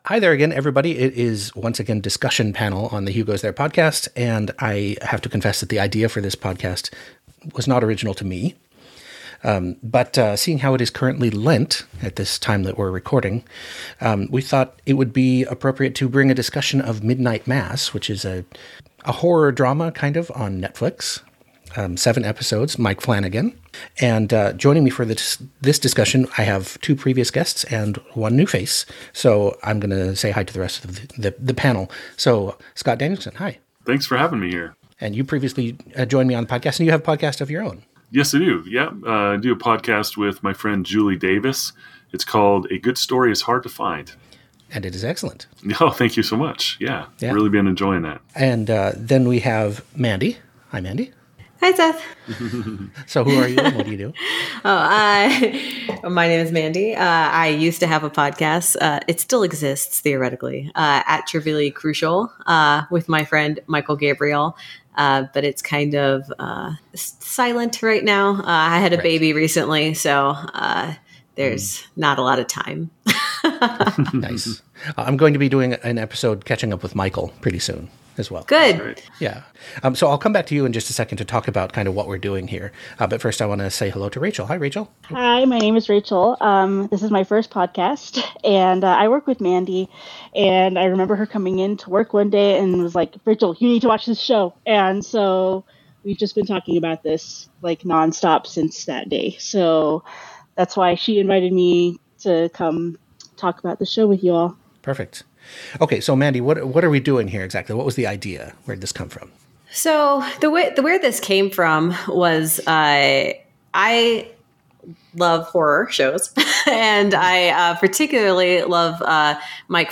It’s Lent season as this episode is posted, and what better genre show to consider than the excellent Netflix limited series, Midnight Mass. This is just four Christian engineers discussing a…
bonus-episode-midnight-mass-discussion-panel.mp3